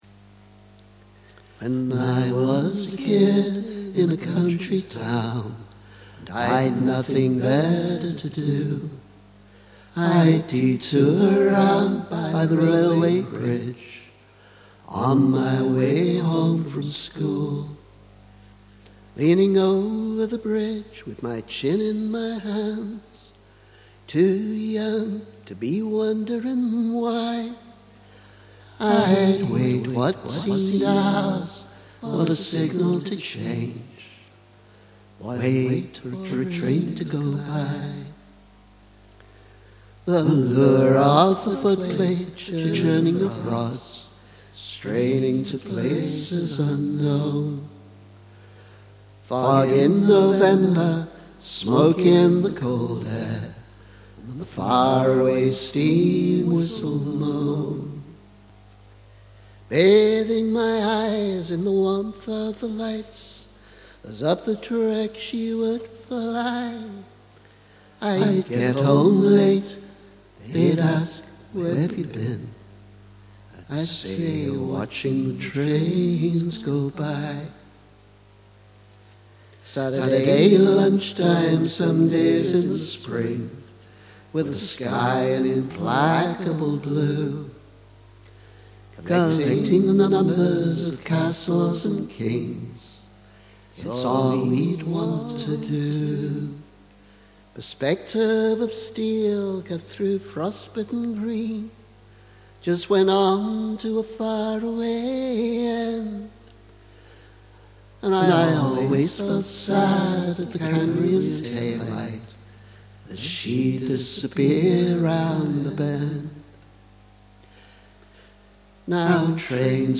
Sketch of a harmony version